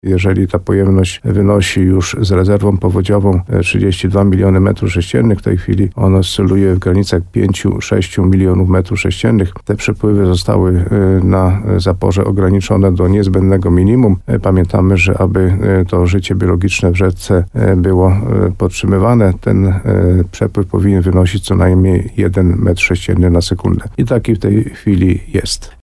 Jak zapewniał w programie Słowo za Słowo w radiu RDN Nowy Sącz wójt Karol Górski, chodzi przede wszystkim o zachowanie ekosystemu rzeki Ropa.